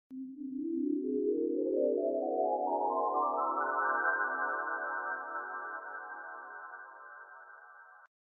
Play, download and share vv_magical appearance original sound button!!!!
vv-magical-appearance.mp3